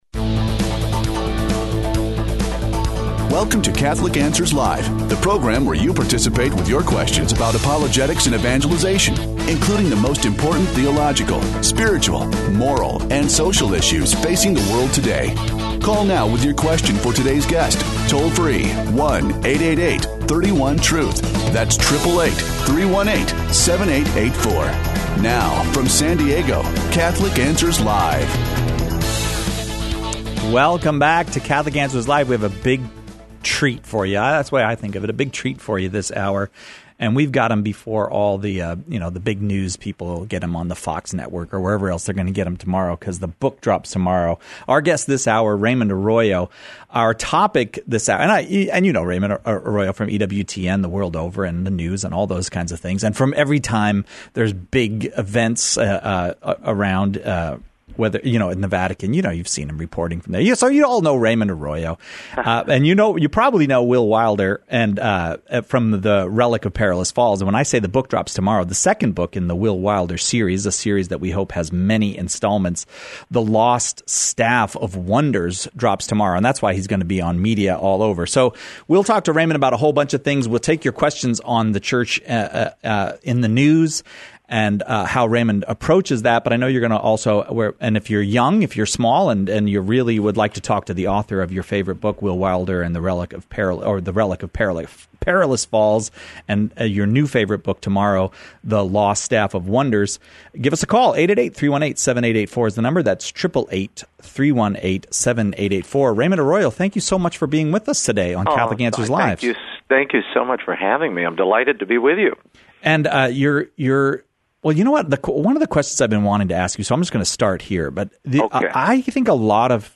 We searched the world over, and found Raymond Arroyo to talk with us about the Church in the News.